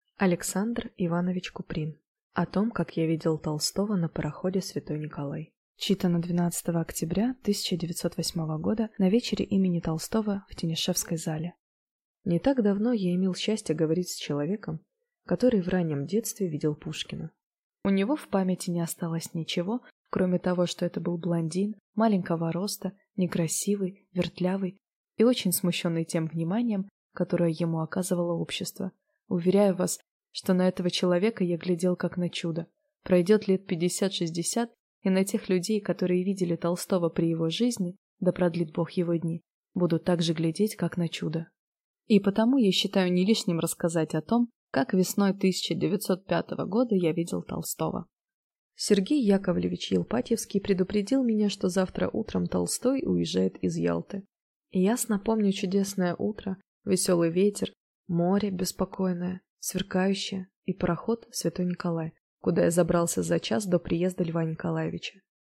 Аудиокнига О том, как я видел Толстого на пароходе «Св. Николай» | Библиотека аудиокниг